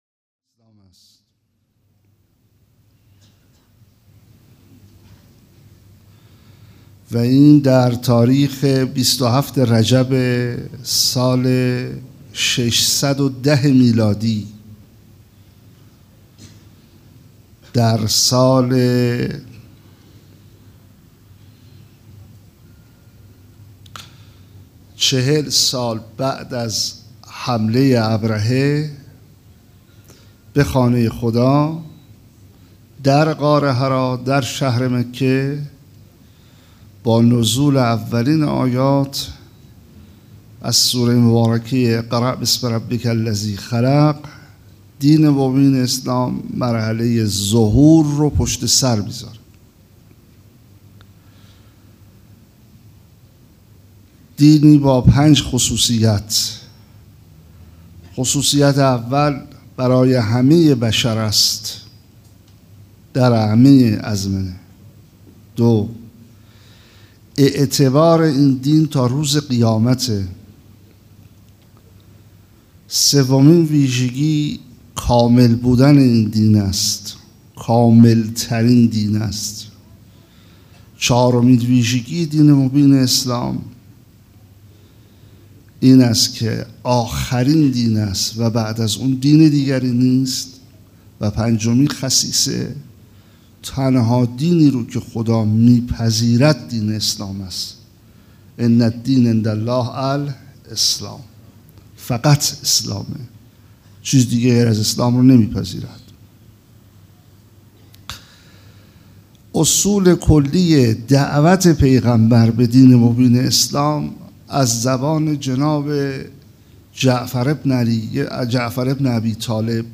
سخنرانی
سبک اثــر سخنرانی